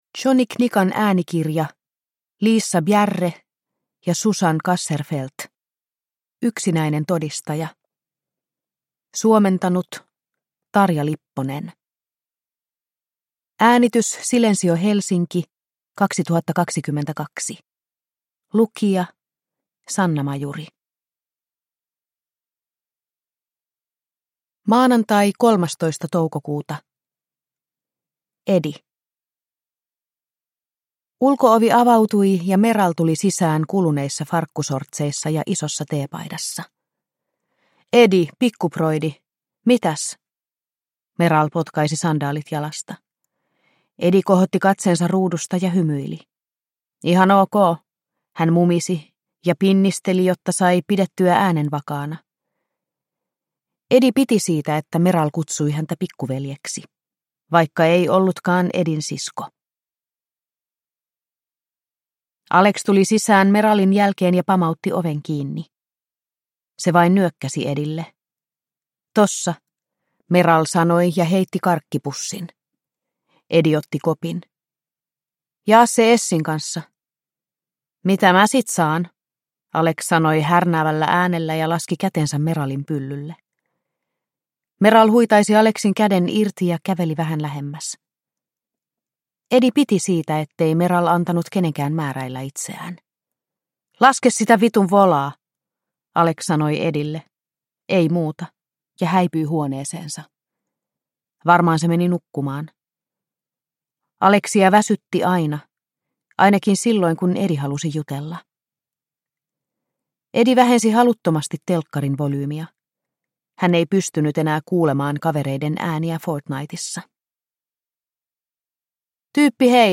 Yksinäinen todistaja – Ljudbok